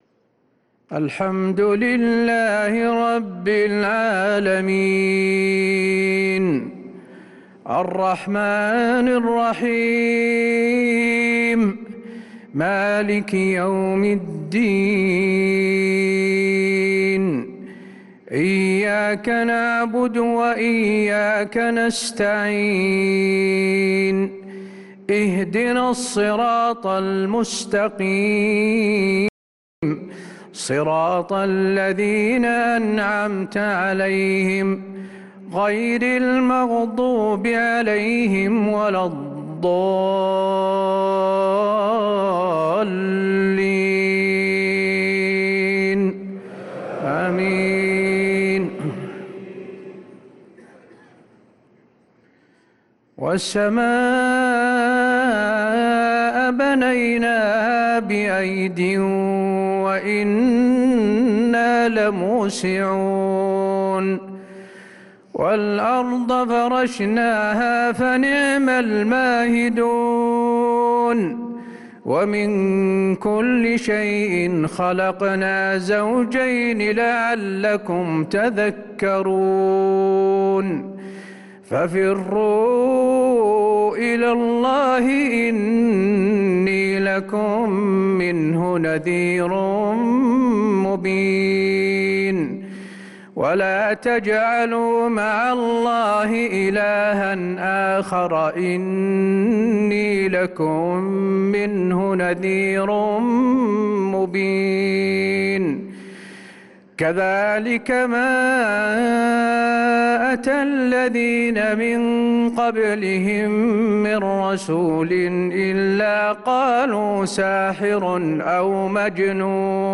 عشاء الأربعاء 5-9-1446هـ خواتيم سورة الذاريات47-60 | Isha prayer from Surat ad-Dhariyat 5-3-2025 > 1446 🕌 > الفروض - تلاوات الحرمين